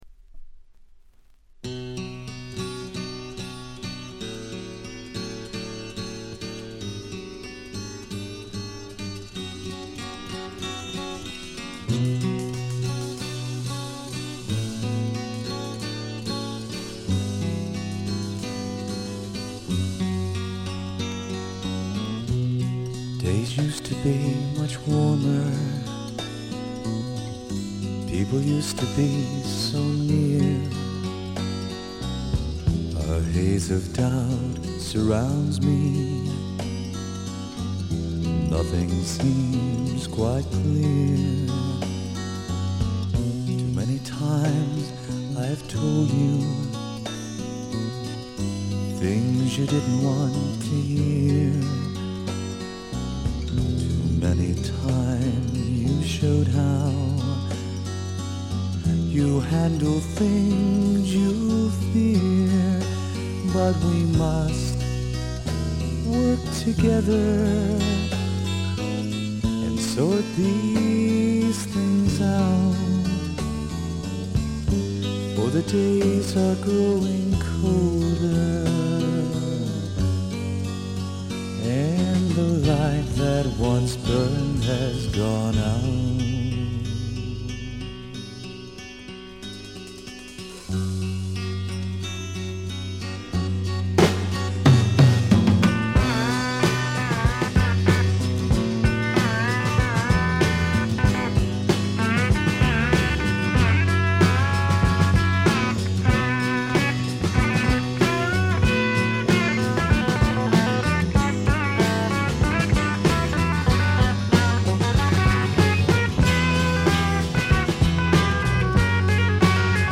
軽微なチリプチが少し。散発的なプツ音が少し。
試聴曲は現品からの取り込み音源です。